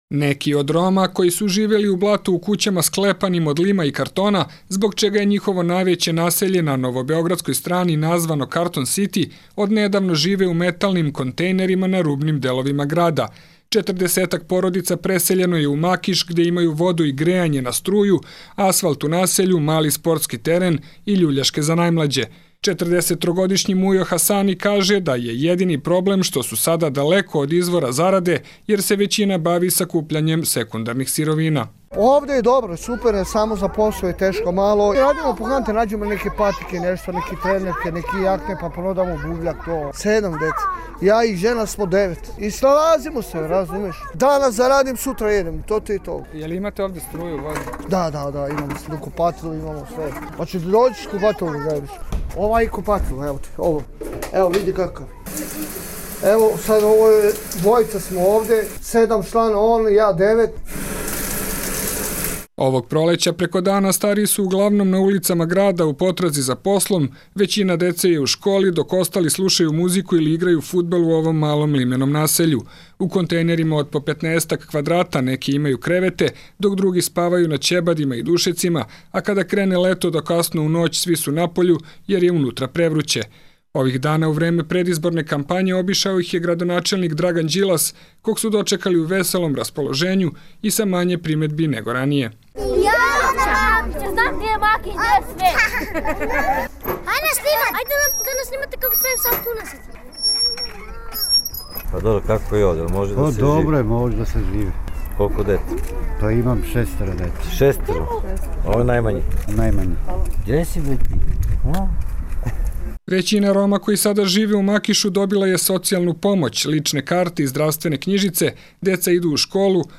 RSE reportaža: Kako žive Romi u Makišu